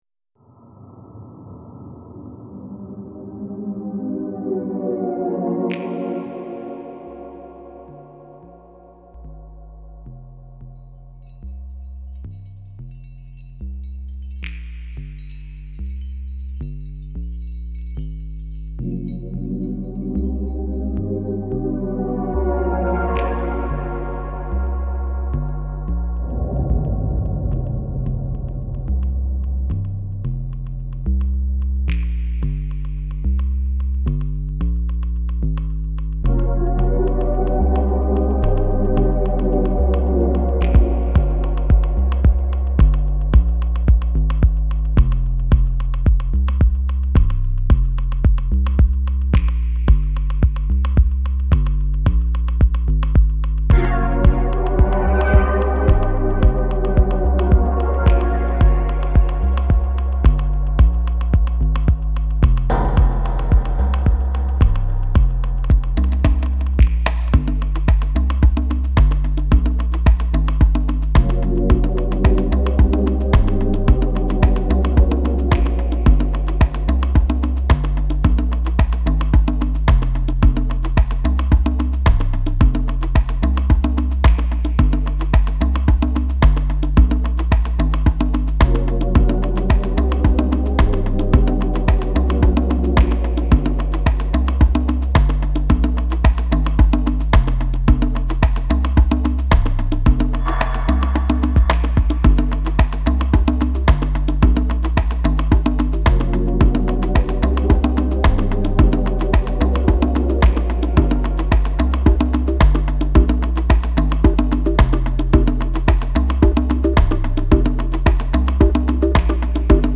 Genre: Lounge, Downtempo.